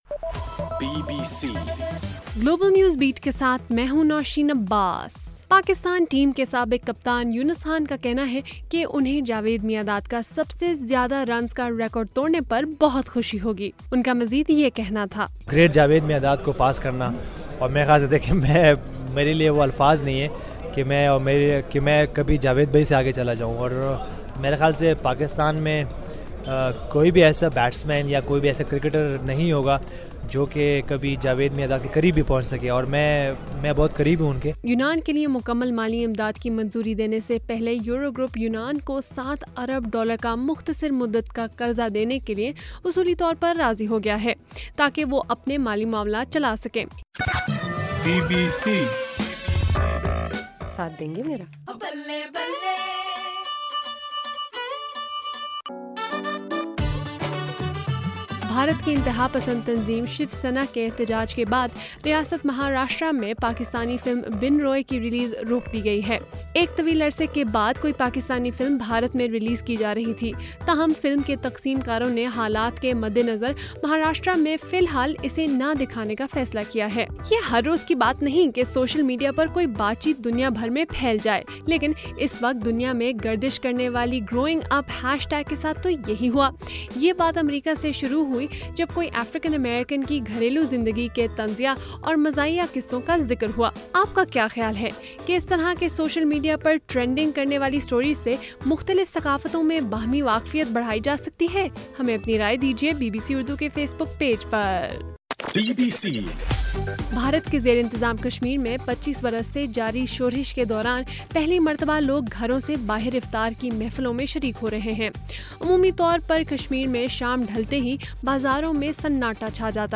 جولائی 16: رات 10 بجے کا گلوبل نیوز بیٹ بُلیٹن